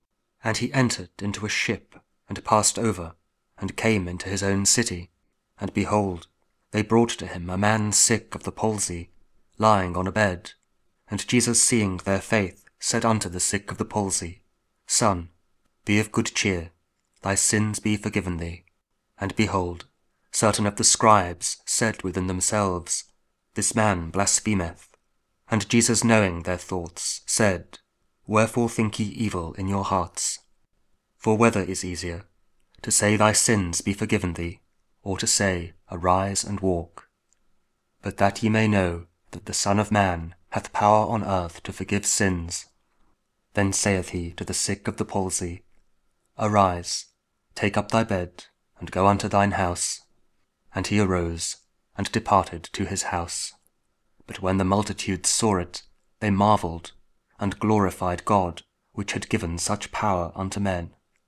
Matthew 9: 1-8 – Week 13 Ordinary Time, Thursday (King James Audio Bible KJV, Spoken Word)